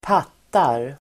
Ladda ner uttalet
Uttal: [²p'at:ar]